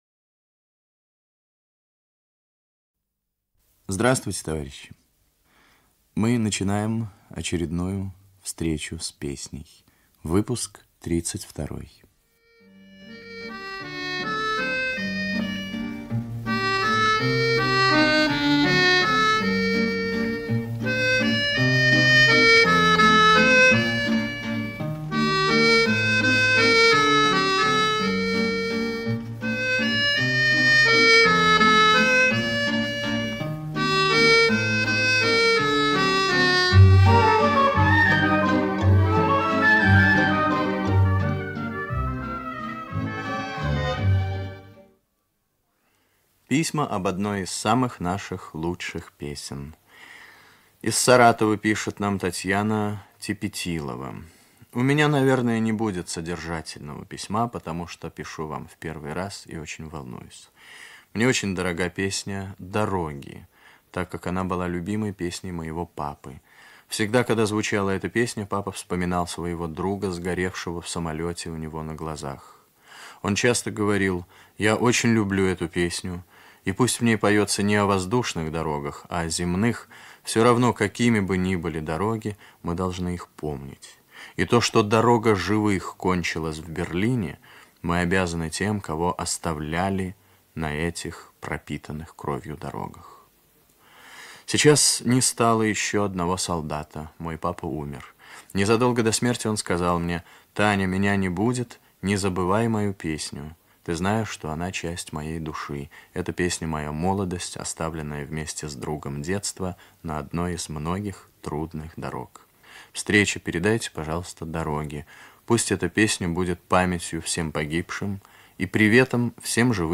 Русские народные песни и песни советских композиторов